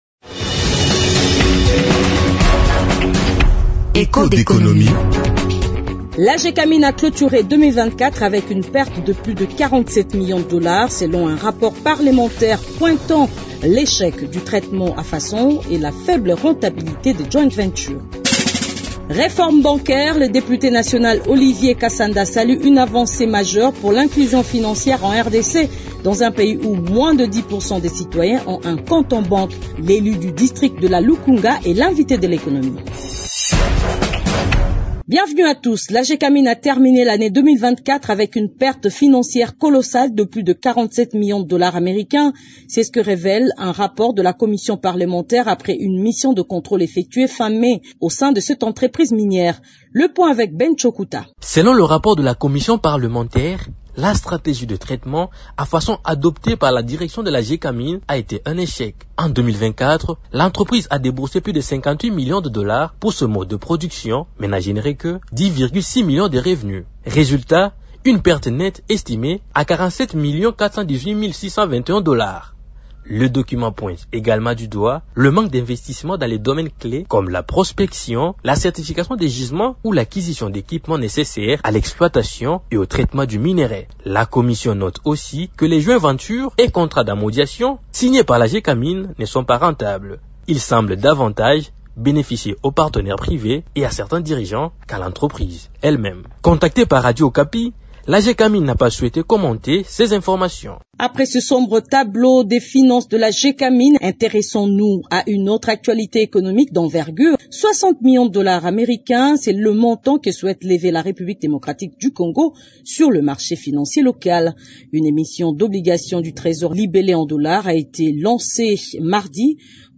Il est l’invité de l’émission Echos d’économie de ce mercredi 18 juin 2025.